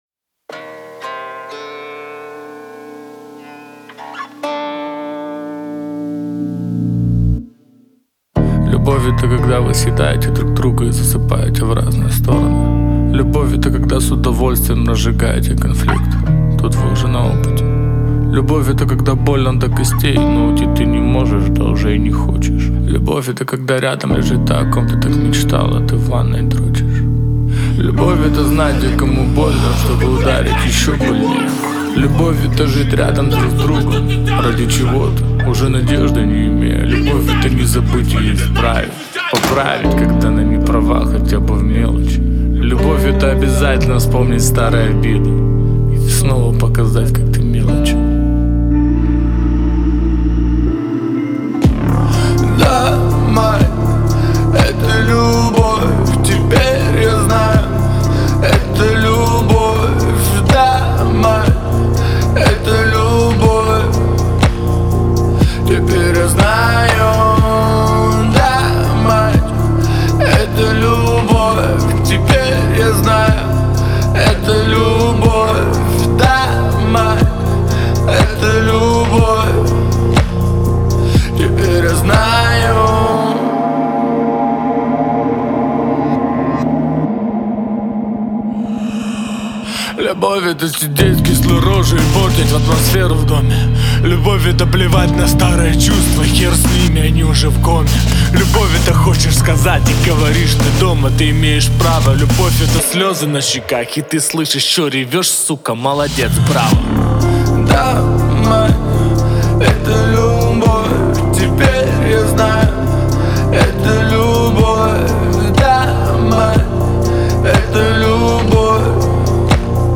в жанре поп-рок